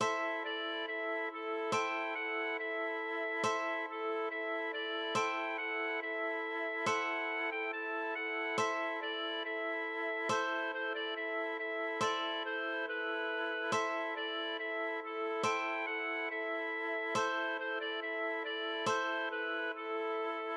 Ein Lied aus der Baronie Hutt, um 600 BF
} myMusic = { << \chords { \germanChords \set chordChanges=##t \set Staff.midiInstrument="acoustic guitar (nylon)" a1:m | a1:m | a1:m | a1:m | a1:m | a1:m | a1:m | a1:m | a1:m | a1:m | a1:m | a1:m | } \relative c'' { \time 4/4 \tempo 4=140 \key a \minor \set Staff.midiInstrument="clarinet" e4 d4 e4 a,4 | g'4 fis4 e2 | e4 a,4 e'4 d4 | g4 fis4 e2 | fis4. g8 a4 fis4 | e4 d4 e2 | c4 c8 d8 e4 e4 | d4 c4 b2 | e4 d4 e4 a,4 | g'4 fis4 e2 | c4 c8 d8 e4 d4 | c4 b4 a2 \fine } \addlyrics { \set stanza = "I. " Weit der Ruf des | Schirch er- schallt' | ü- ber'm dunk- len | Fei- de- wald.